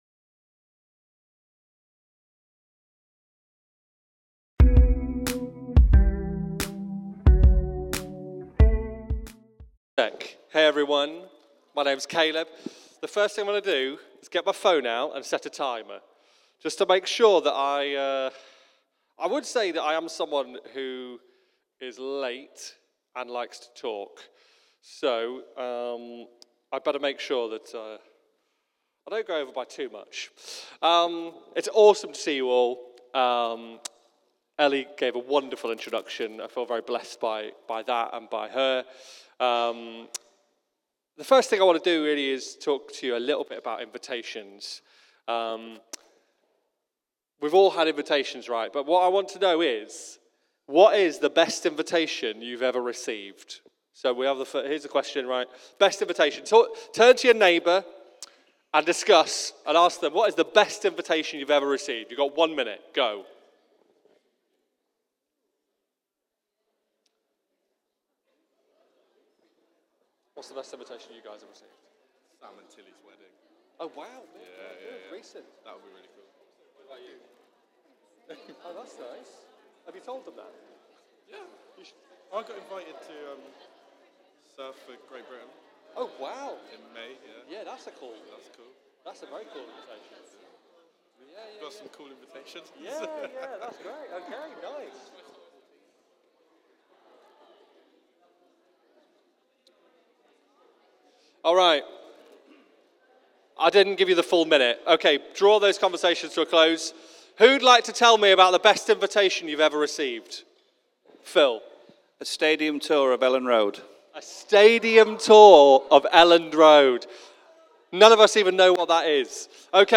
Rediscover Church Newton Abbot | Sunday Messages